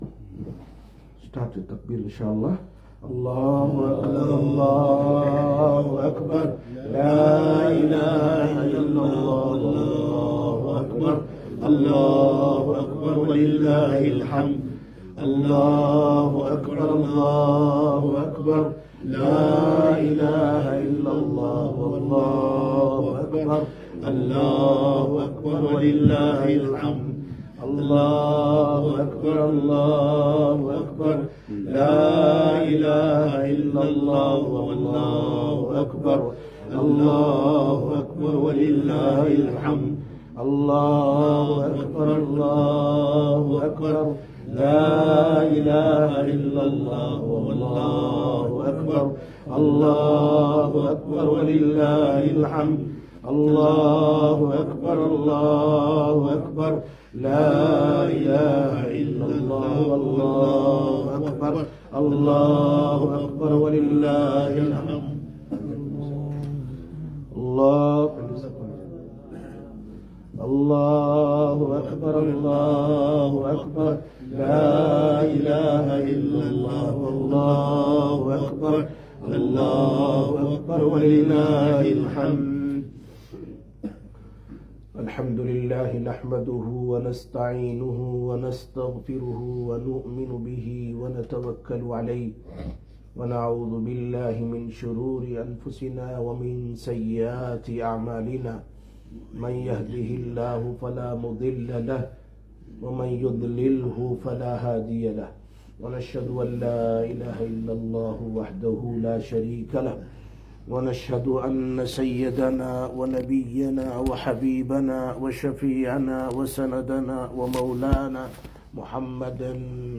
28/06/2023 EID Bayaan, Masjid Quba